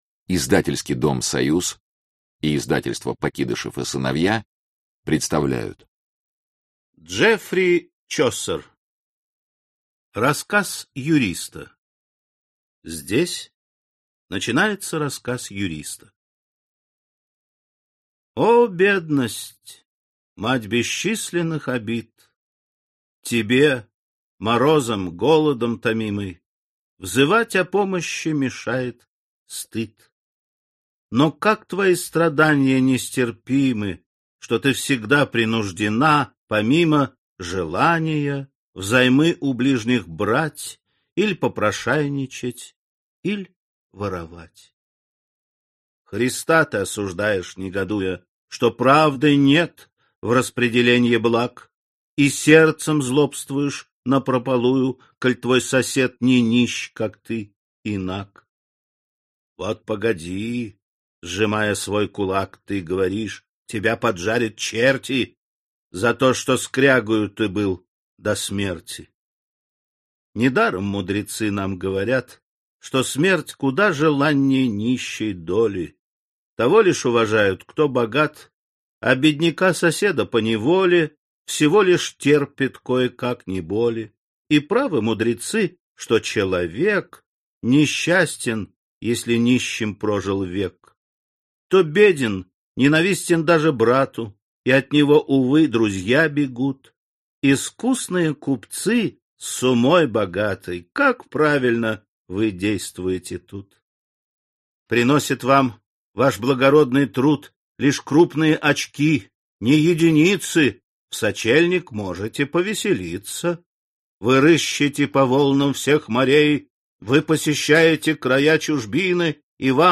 Аудиокнига Рассказы | Библиотека аудиокниг